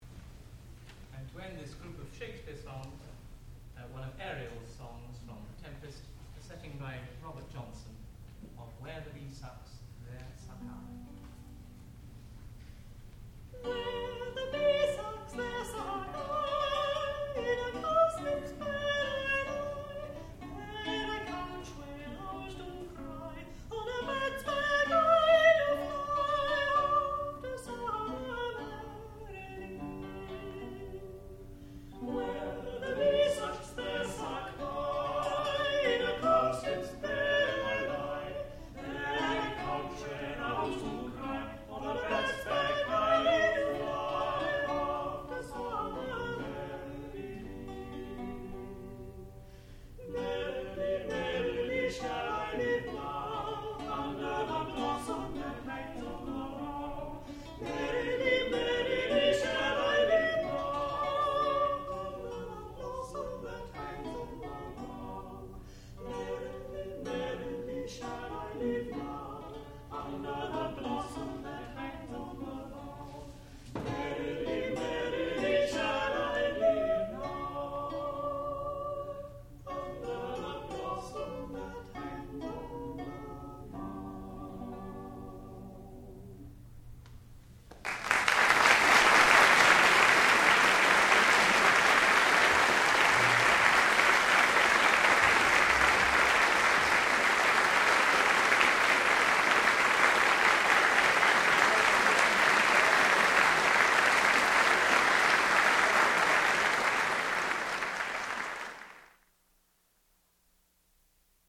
sound recording-musical
classical music
tenor
soprano
lute